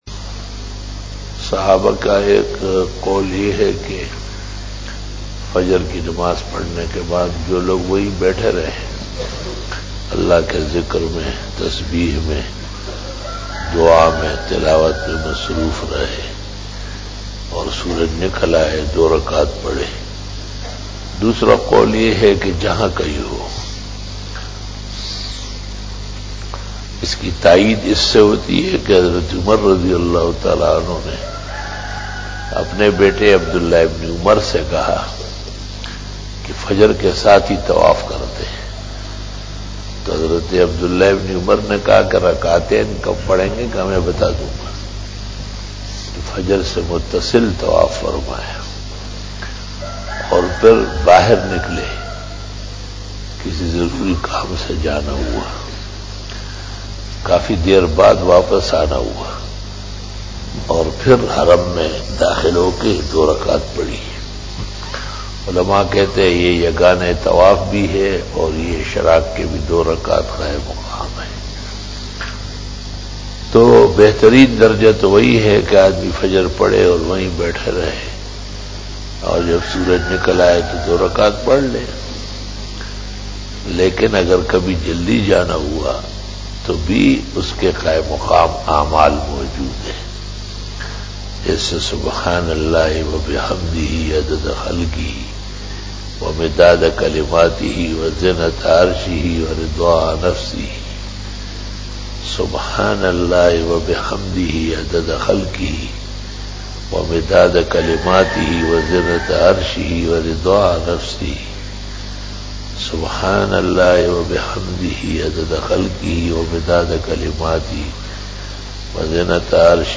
After Fajar Byan